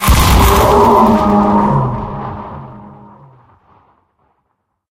4aef571f59 Divergent / mods / Soundscape Overhaul / gamedata / sounds / monsters / poltergeist / death_0.ogg 31 KiB (Stored with Git LFS) Raw History
death_0.ogg